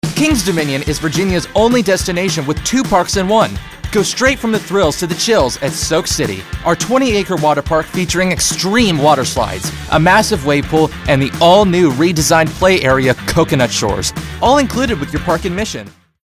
Water Park announcer, high-energy, retail, young adult